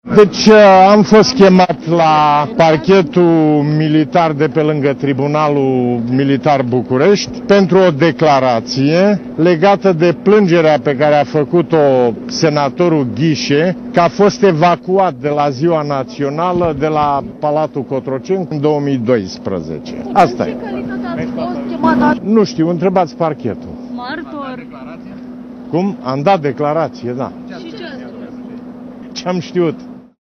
Am fost chemat la Parchetul de pe lângă Tribunalul Militar București pentru o declarație legată de plângerea pe care a făcut-o senatorul Ghișe că a fost evacuat de la ceremonia desfășurată la Palatul Cotroceni cu ocazia Zilei Naționale din 2012. Am dat o declarație“, a spus Traian Băsescu, la ieșirea din sediul Parchetului Militar.